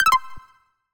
Universal UI SFX / Basic Menu Navigation
Menu_Navigation01_Close.wav